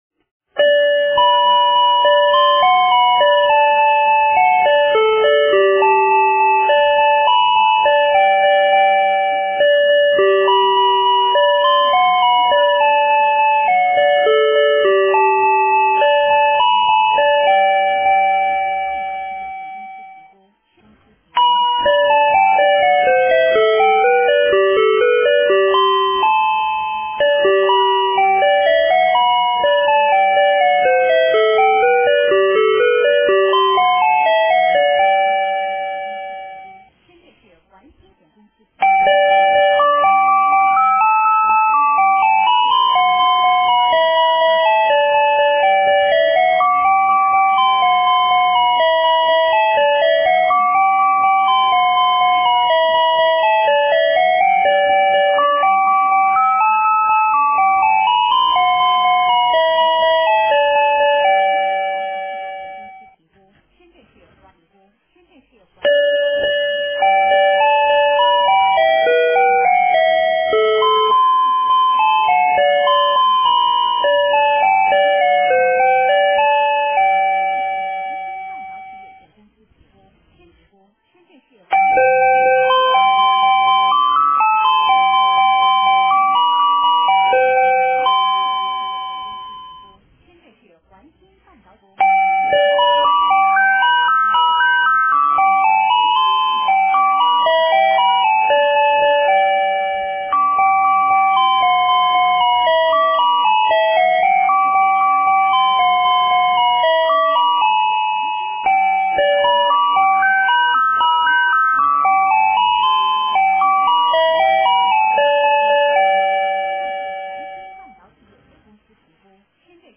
和弦音质好，音量大音色逼真。
音乐芯片曲目：八和弦儿歌音乐12首
世界经典名曲，不受版权限制。
对效果和旋律进行了新的音效升级。